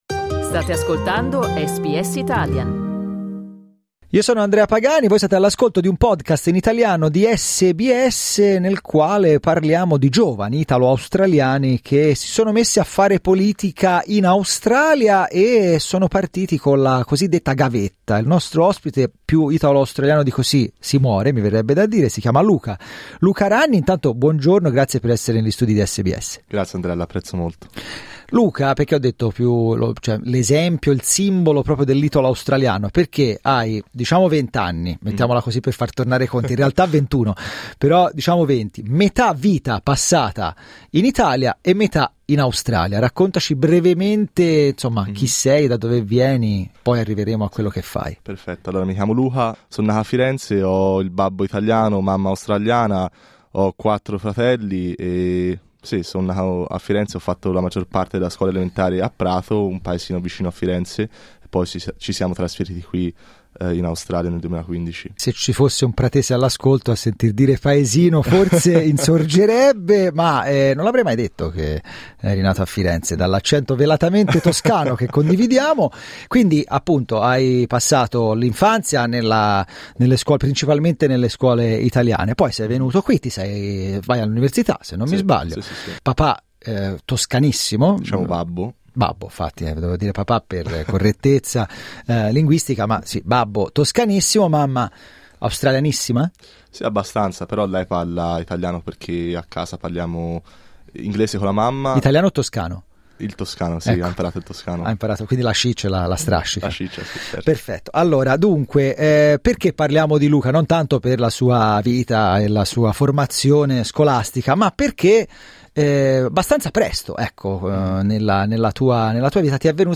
Luca Ragni nei nostri studi